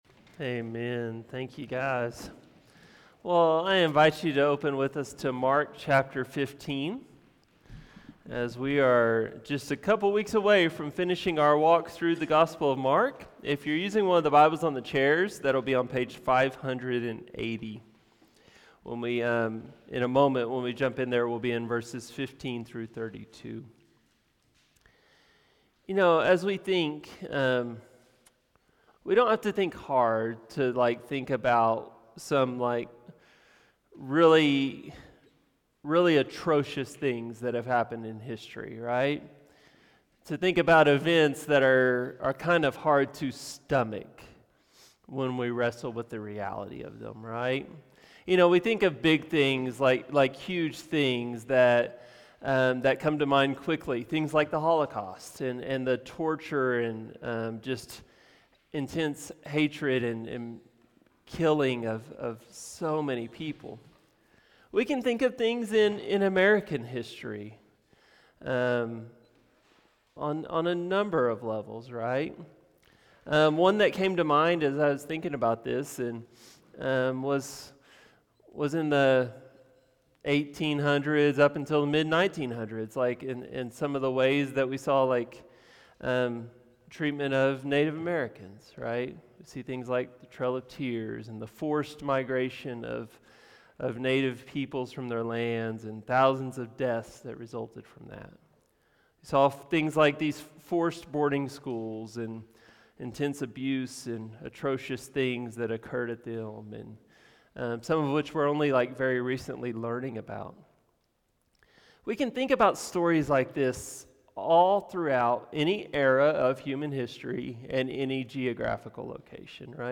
A message from the series "The Gospel of Mark."